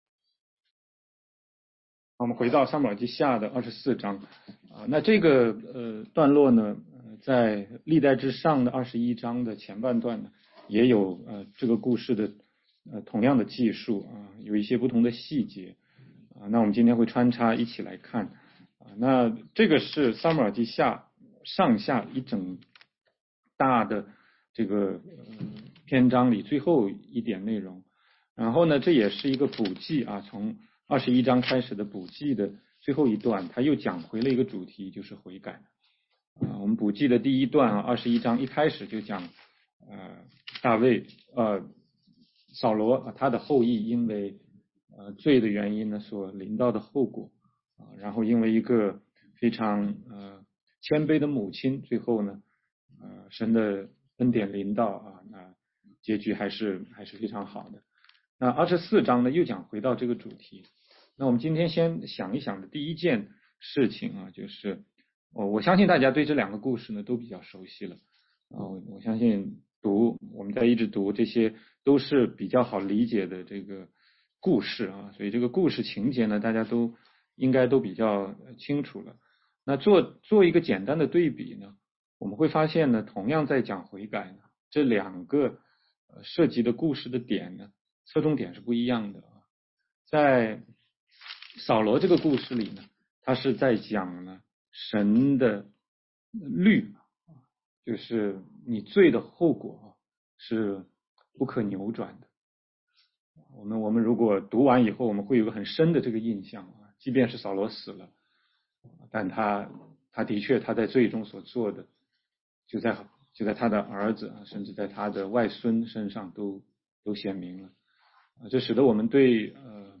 16街讲道录音 - 撒母耳记下24章：大卫数点军兵
全中文查经